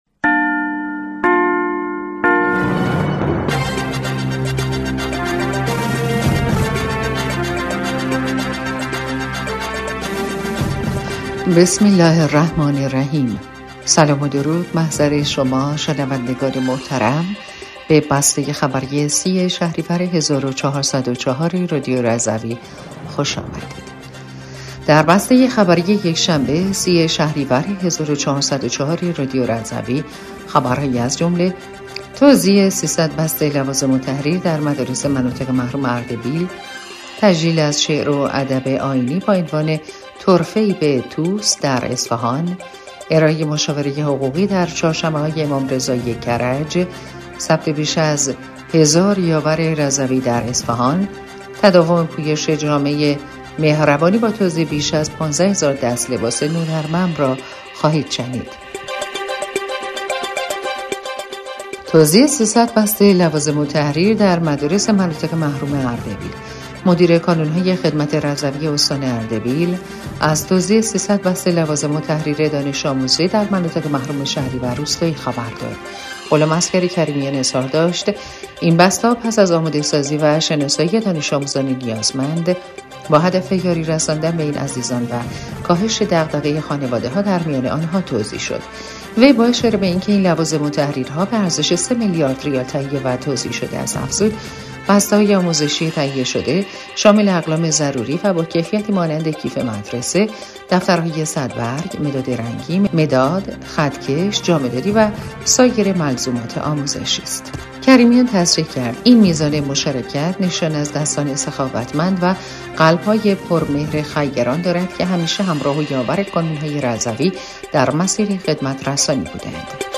بسته خبری ۳۰ شهریور ۱۴۰۴ رادیو رضوی؛